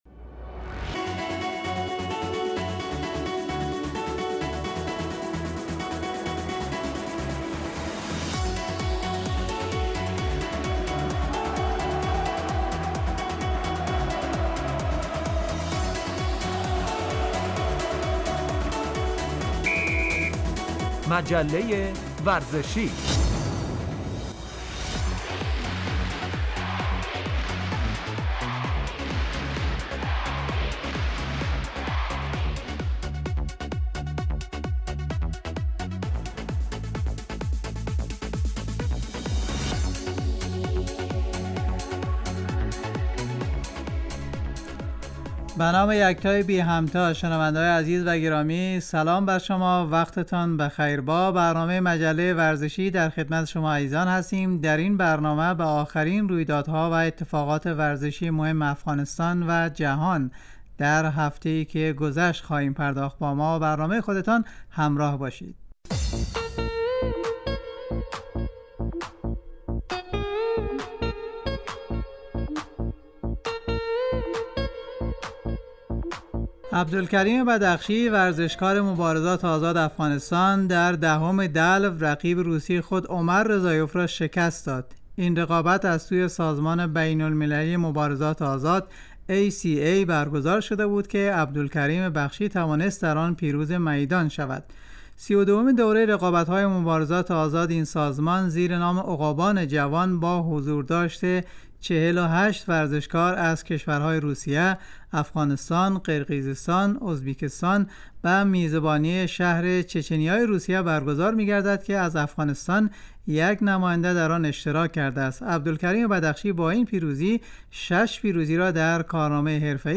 آخرين اخبار و رويدادهاي ورزشي افغانستان و جهان در هفته اي که گذشت به همراه گزارش ومصاحبه و بخش ورزش وسلامتي